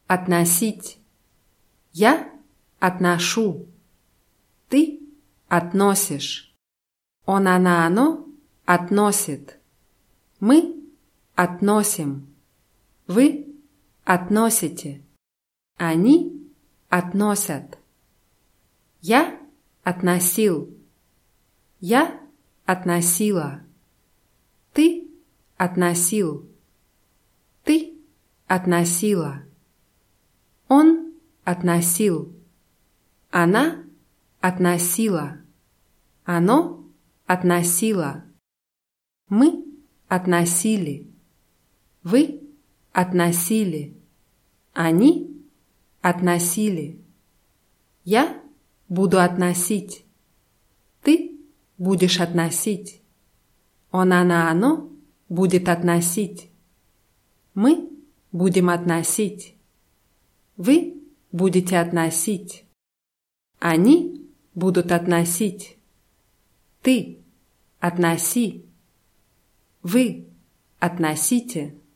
относить [atnaßʲítʲ]